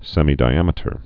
(sĕmē-dī-ămĭ-tər, sĕmī-)